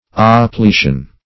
Search Result for " oppletion" : The Collaborative International Dictionary of English v.0.48: Oppletion \Op*ple"tion\, n. The act of filling up, or the state of being filled up; fullness.